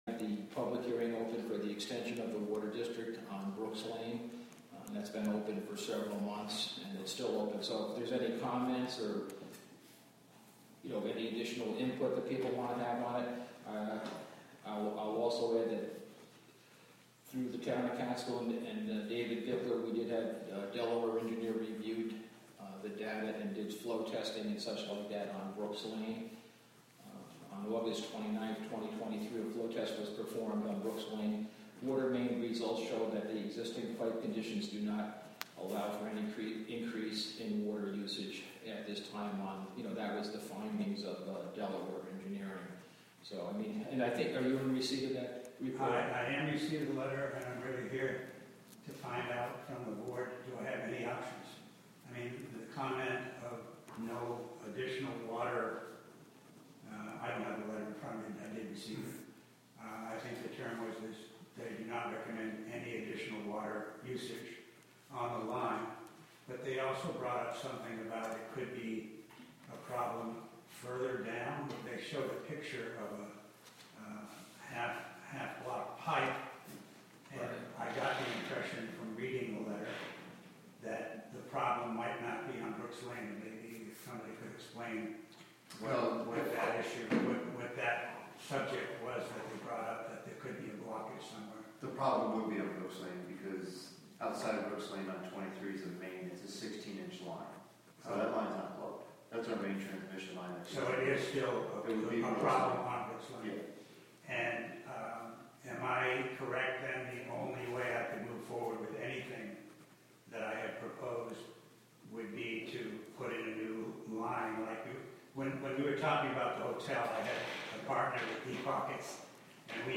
WGXC is partnering with the Town of Catskill to present live audio streams of public meetings.